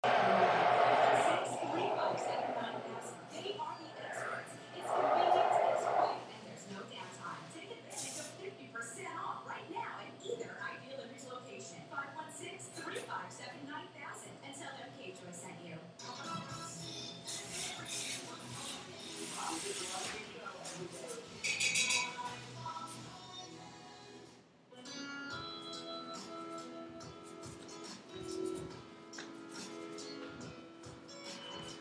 Field Recording
CafeontheQuad Taken at CafeontheQuad, Friday, 4/15 at 8:40 AM Sounds heard are milk steaming, a spoon tapping a cup, the espresso machine, the radio, and people walking by.
Taken at CafeontheQuad, Friday, 4/15 at 8:40 AM
CafeontheQuad.mp3